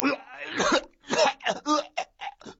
scream8.ogg